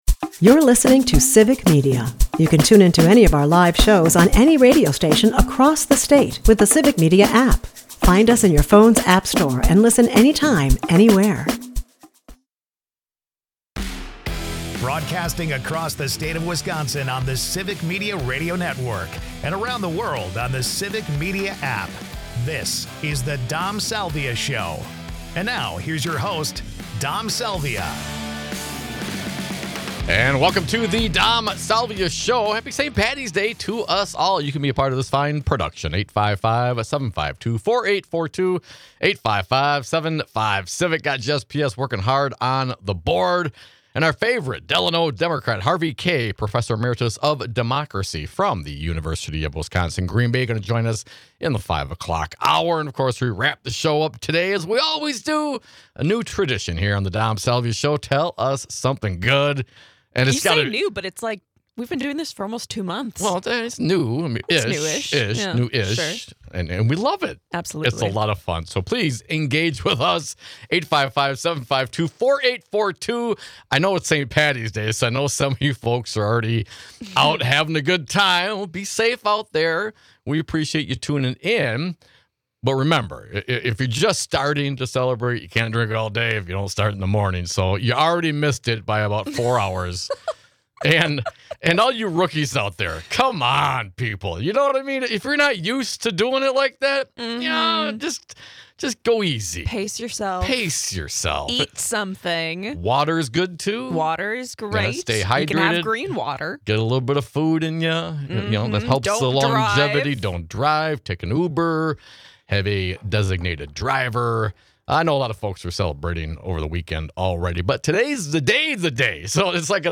Because Musk has already invested $10 Million to this race, we discuss the threat of his involvement to our state's democracy. Our state Democratic Party Chair Ben Wikler talks with Contrarian Jen Rubin on the subject.